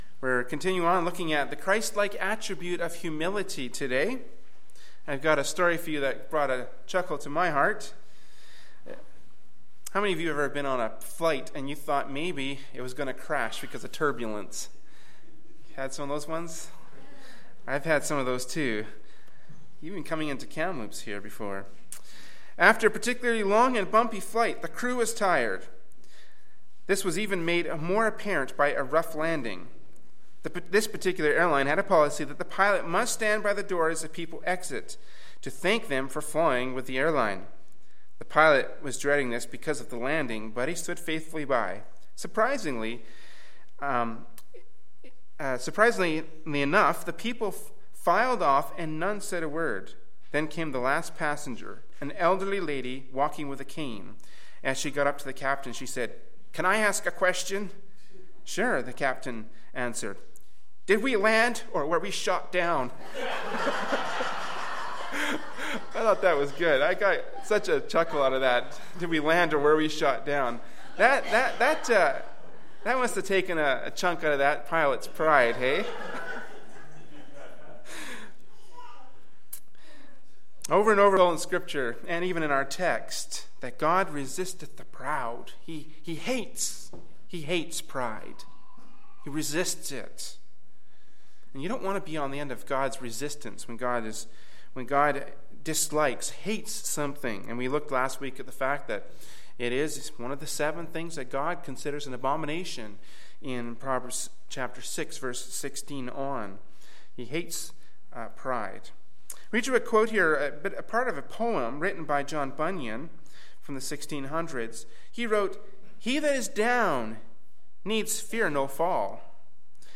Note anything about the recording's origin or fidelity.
Berean Baptist Church Kamloops, B.C. Canada